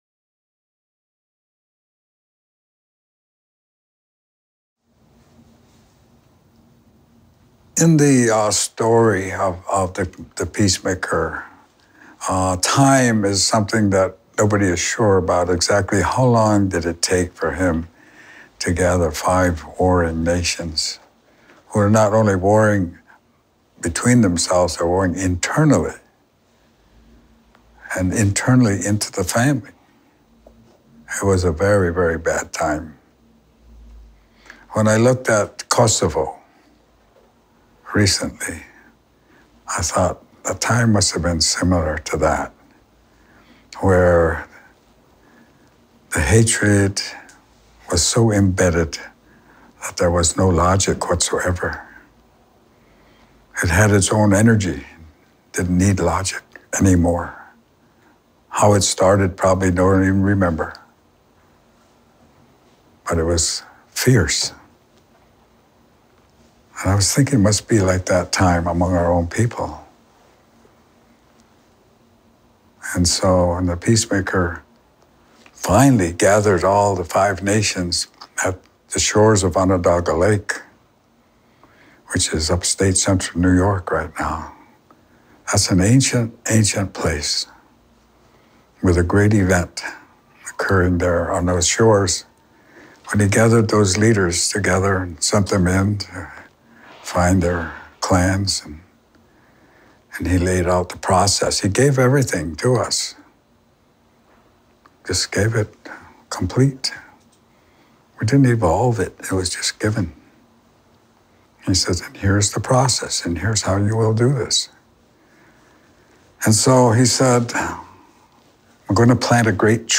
back to 6 Nations | many worlds | rat haus | Index | Search | tree ( PDF | text only formats ) The Peacemaker & the Tadadaho Published September 16, 2015 MP3 recording (10:01, 11.1MB) This short film is part of 8 short, testimonial films , on the Haudenosaunee (Iroquois.)